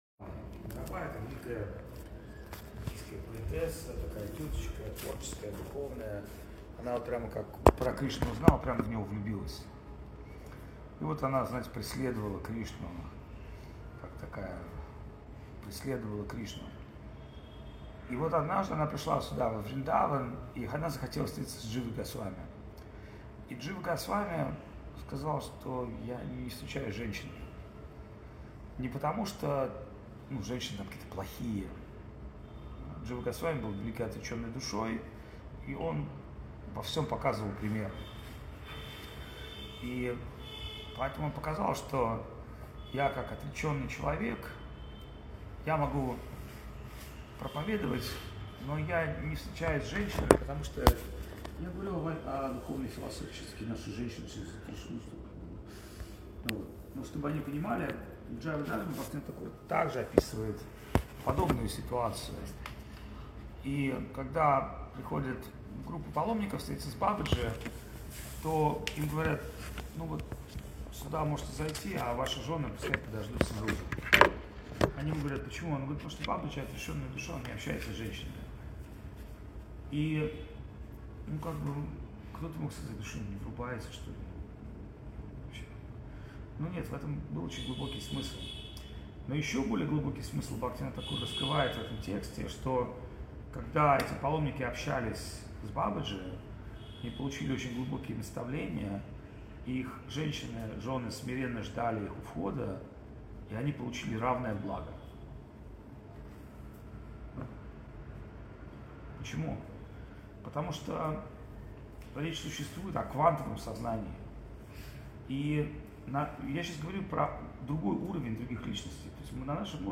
Место: Индия
Лекции полностью